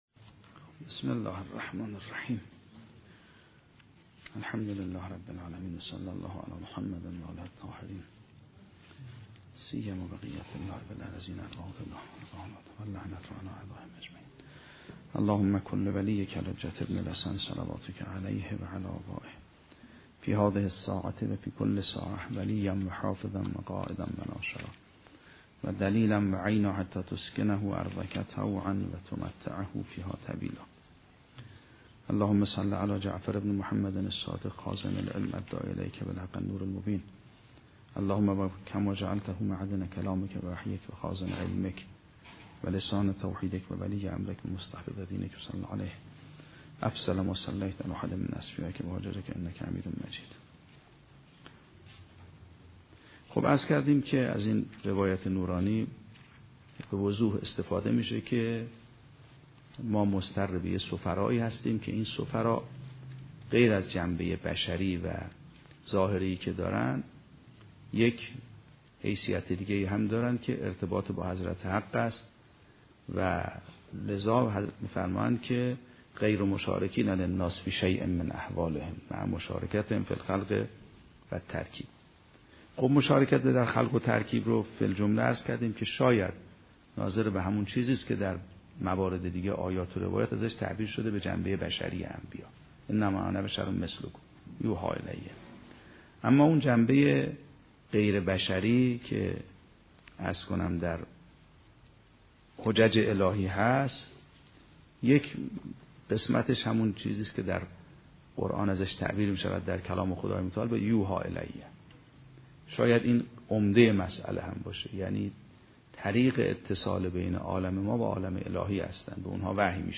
شرح و بررسی کتاب الحجه کافی توسط آیت الله سید محمدمهدی میرباقری به همراه متن سخنرانی ؛ این بخش : ساحت قدسی امام در پرتو روایات نوادر توحید کتاب شریف کافی